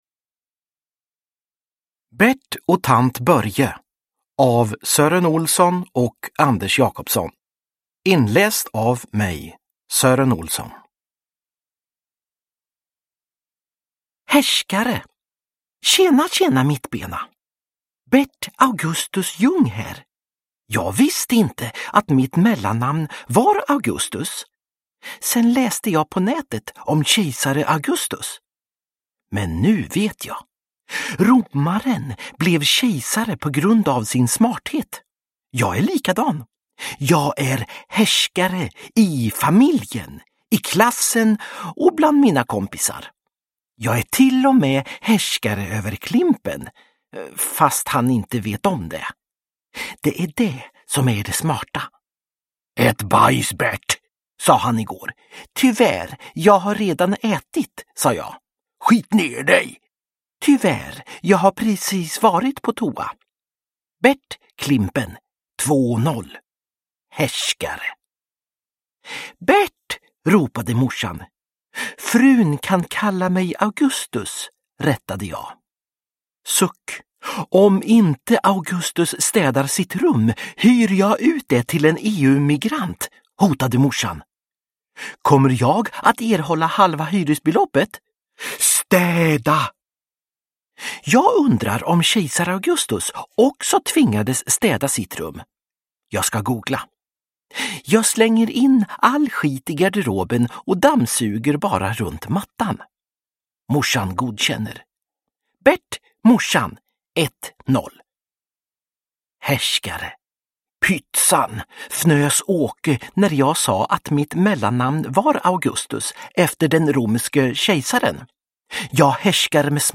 Uppläsare: Sören Olsson